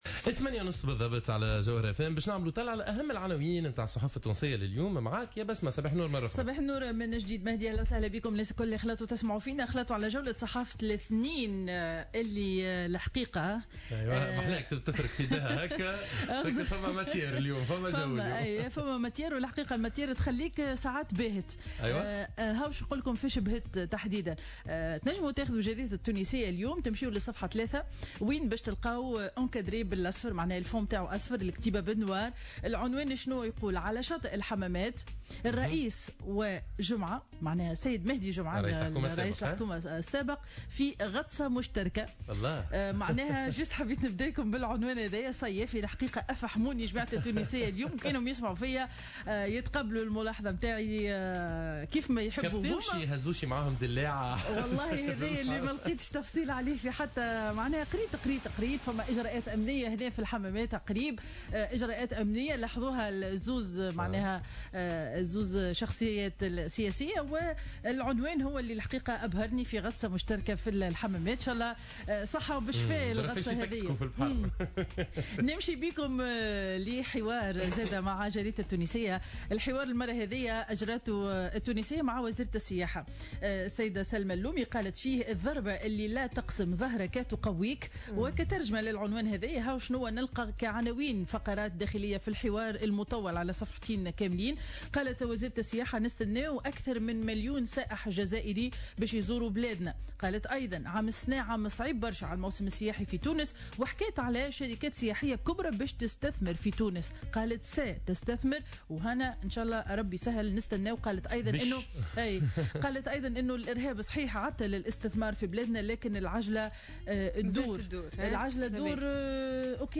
Revue de presse 27/07/2015 à 08:21